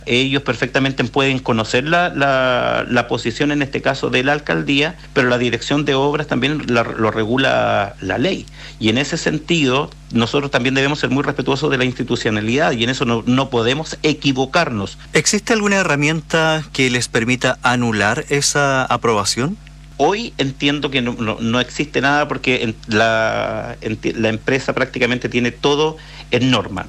En una entrevista exclusiva con Radio Bio Bio, el alcalde de Los Lagos, Víctor Fritz, reiteró su rechazo a la instalación de una piscicultura en el río San Pedro y confirmó que el Concejo Municipal de forma unánime se opone a esta iniciativa.